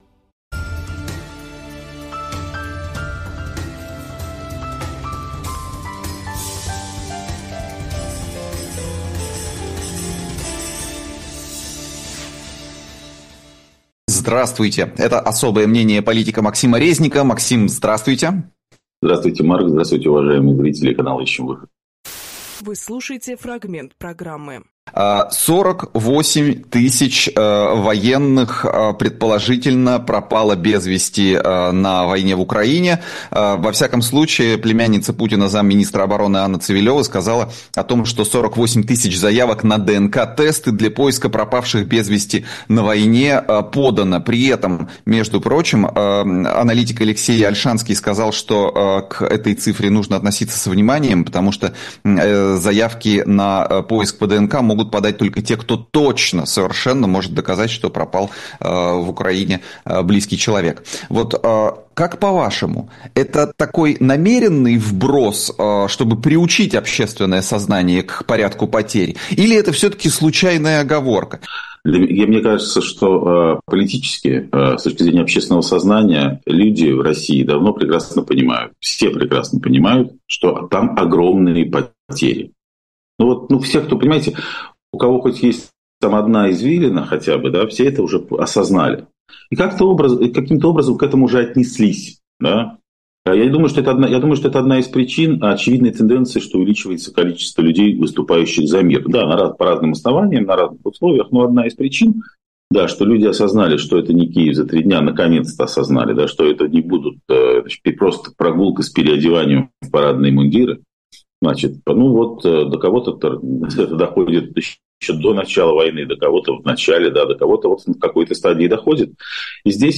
Фрагмент эфира от 05.12.24